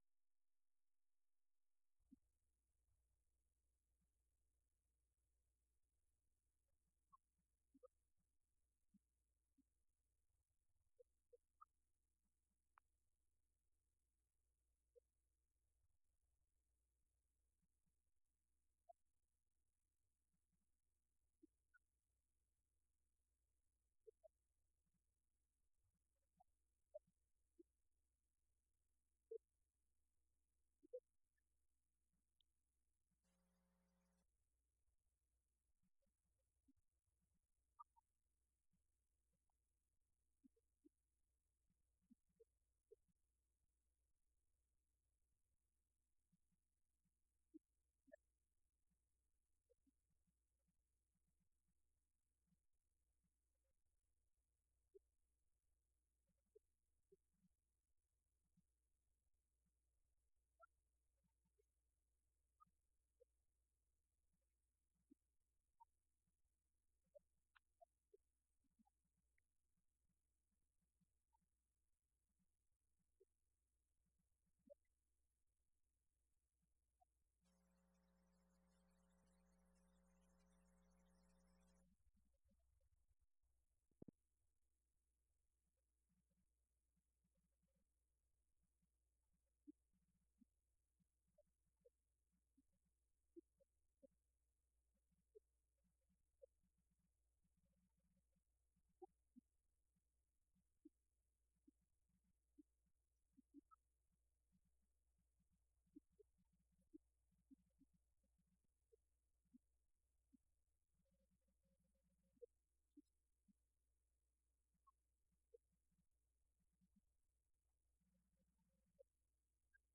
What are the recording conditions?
Event: 6th Annual Southwest Spiritual Growth Workshop